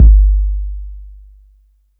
808-Kicks35.wav